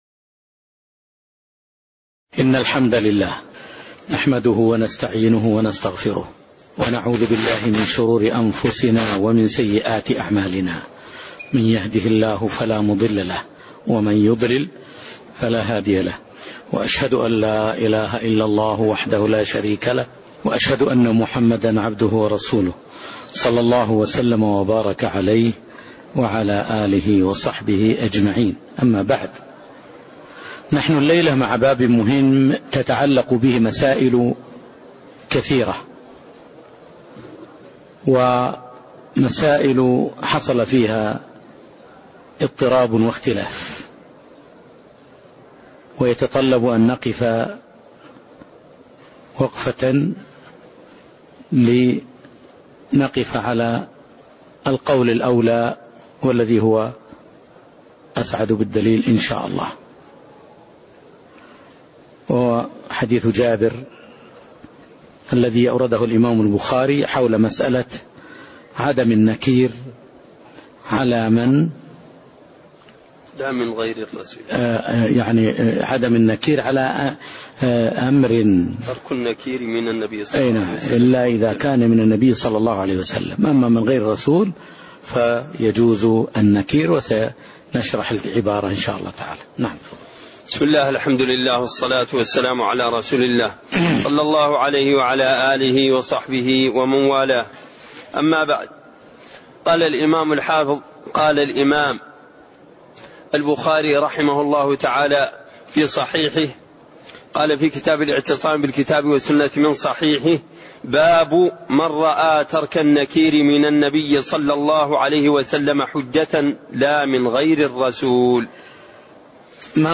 تاريخ النشر ٤ صفر ١٤٣٢ هـ المكان: المسجد النبوي الشيخ